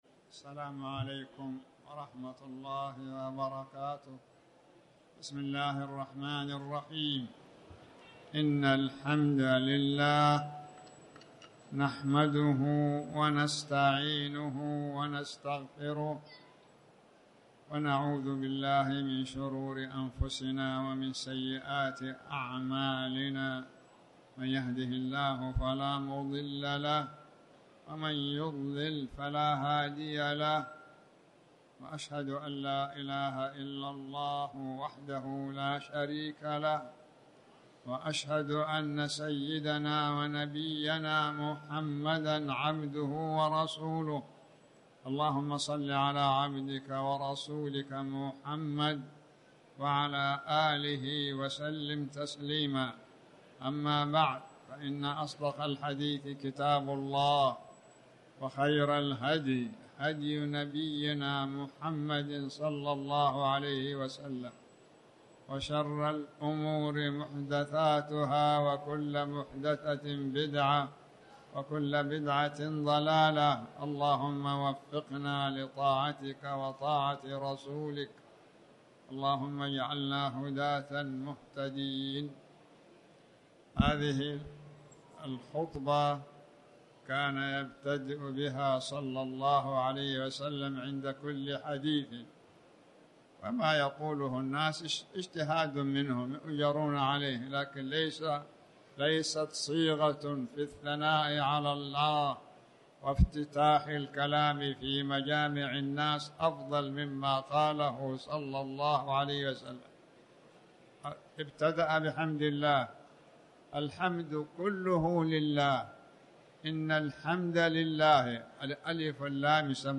تاريخ النشر ١٩ ربيع الأول ١٤٤٠ هـ المكان: المسجد الحرام الشيخ